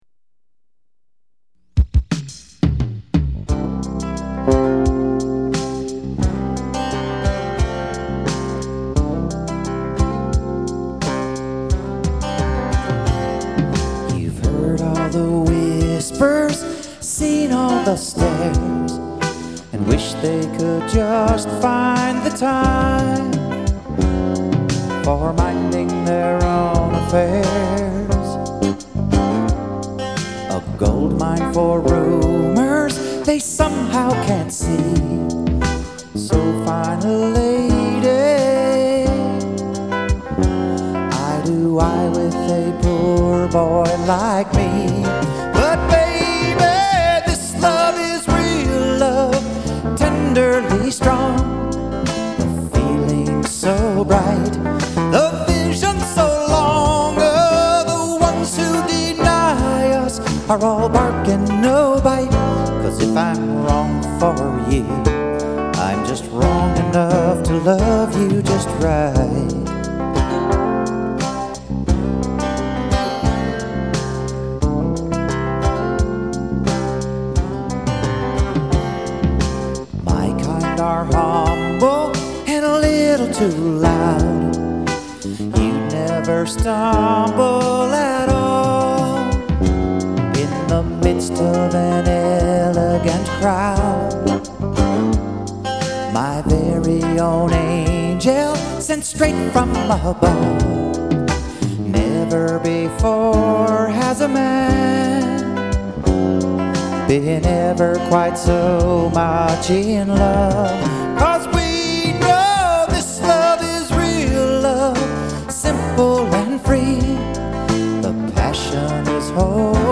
Wrong Enough to Love You Just Right (original, old circa 1981 mp3 demo